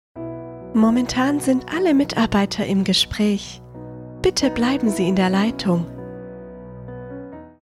Professionelle Sprecherin
Hotline
Hotline-Haus-Nazareth-Sigmaringen-neu-2.mp3